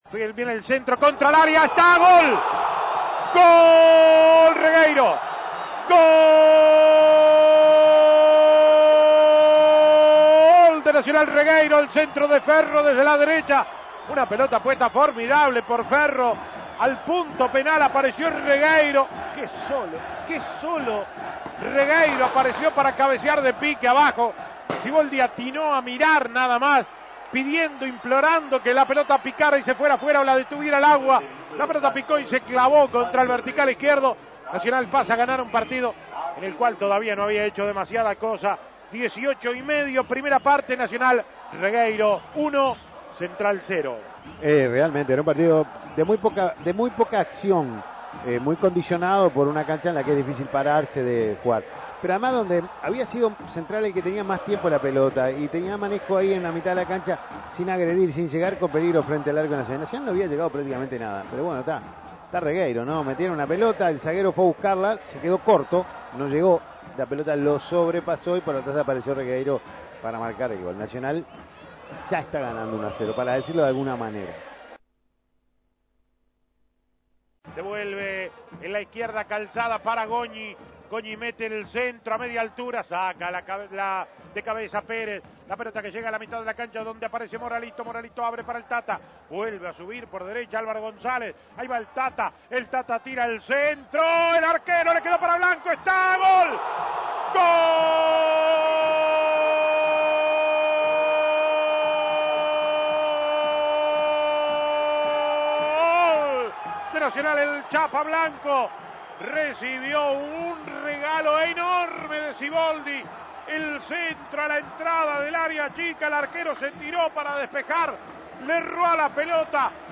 Goles y comentarios Escuche los goles de Nacional ante Central Español Imprimir A- A A+ El tricolor venció a Central Español con goles de Mario Regueiro y Sergio Blanco.